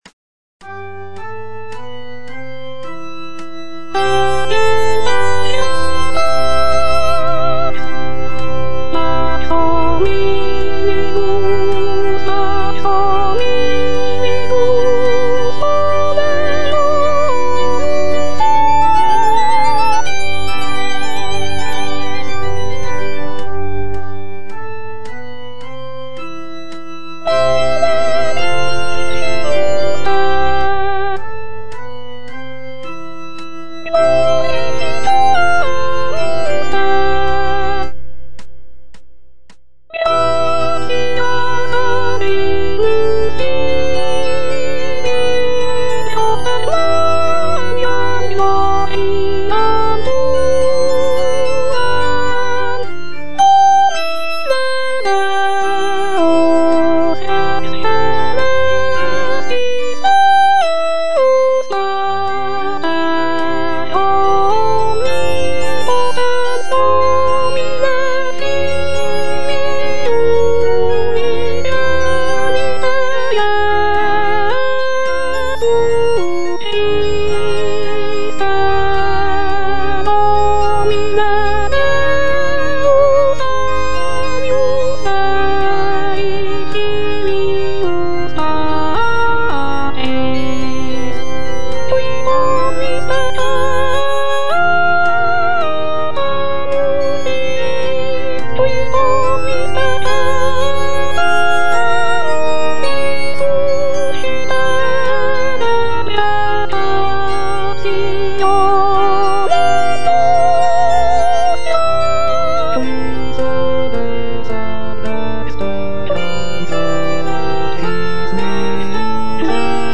J.G. RHEINBERGER - MASS IN C OP. 169 Gloria - Soprano (Voice with metronome) Ads stop: auto-stop
The "Mass in C op. 169" is a choral composition written by Josef Gabriel Rheinberger, a German composer and organist.
It is composed for SATB choir, soloists, organ, and orchestra.